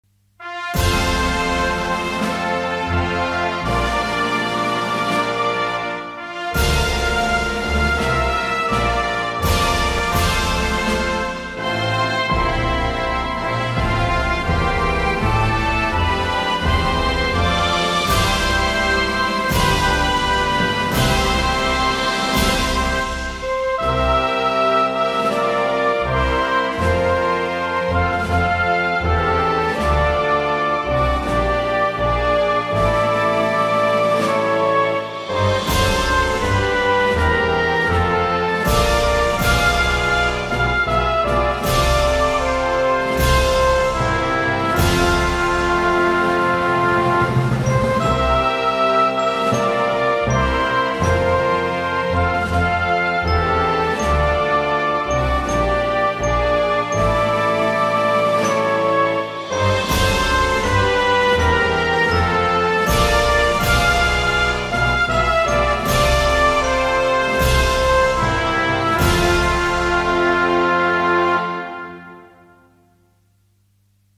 Инструментальная версия национального гимна Дании